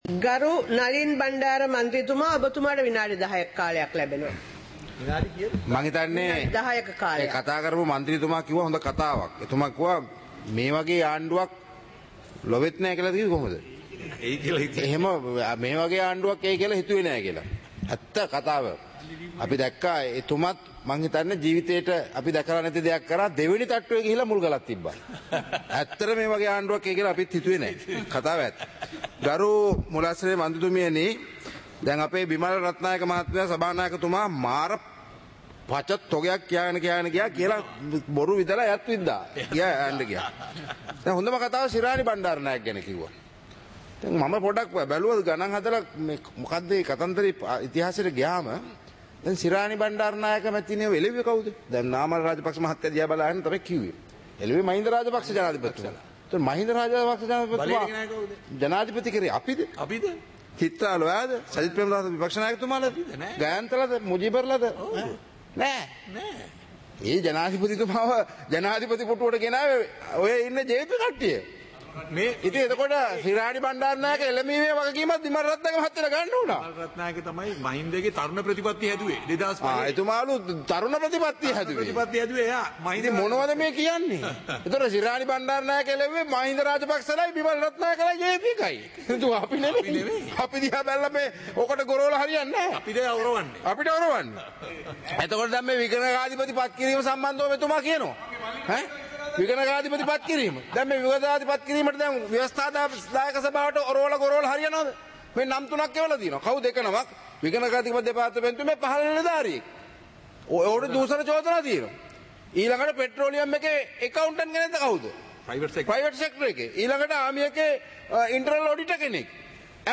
சபை நடவடிக்கைமுறை (2026-01-09)
நேரலை - பதிவுருத்தப்பட்ட